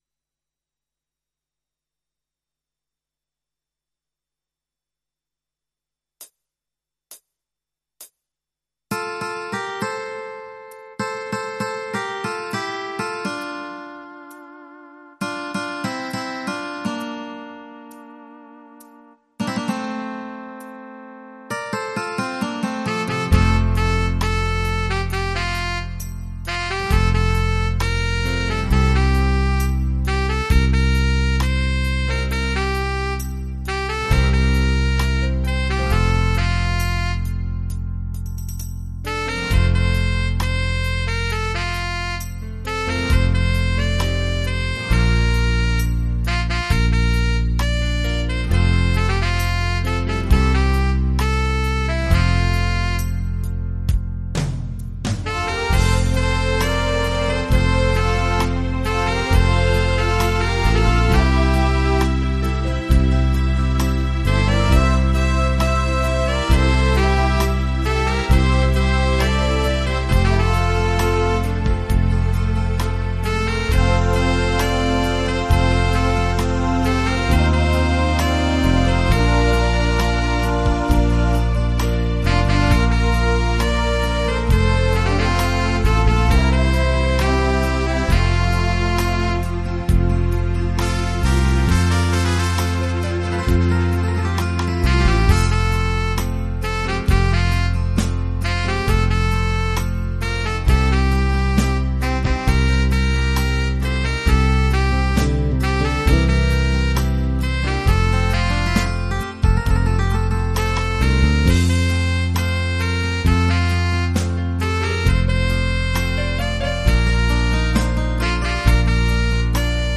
versión instrumental multipista